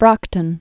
(brŏktən)